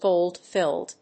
アクセントgóld‐fílled